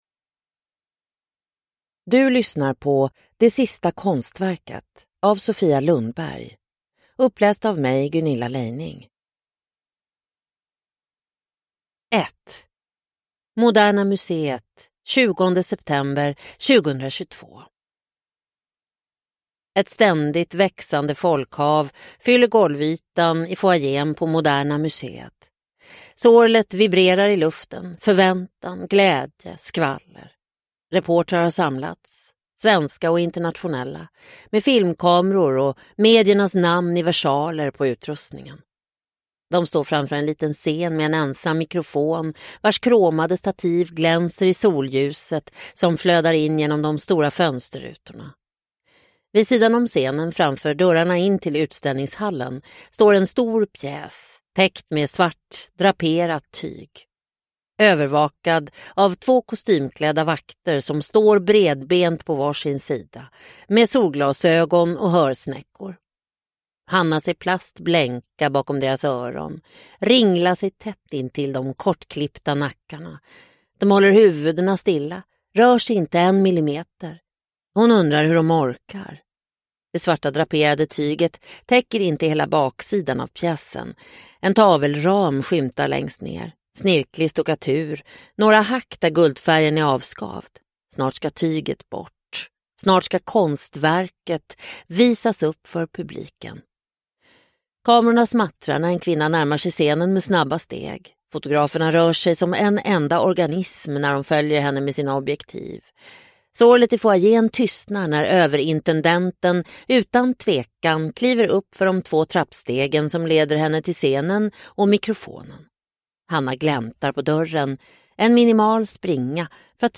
Det sista konstverket – Ljudbok – Laddas ner